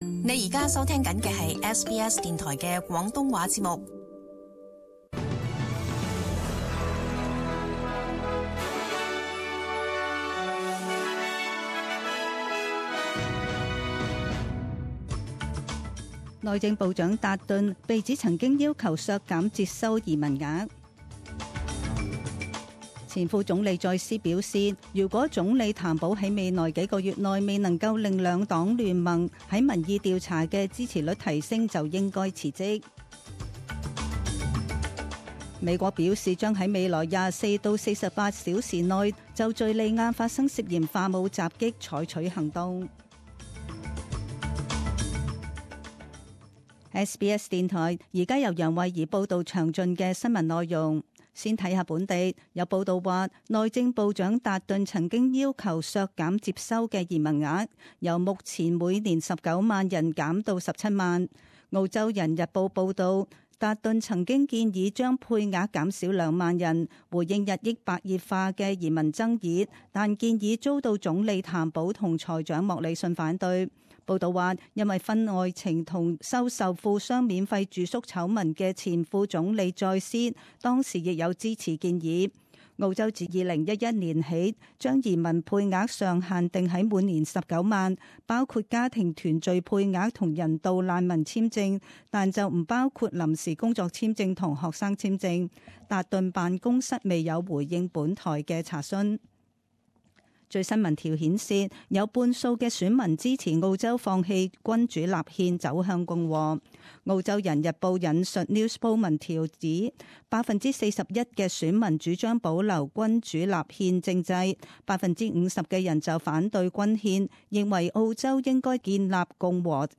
SBS中文新闻 （四月十日）
请收听本台为大家准备的详尽早晨新闻。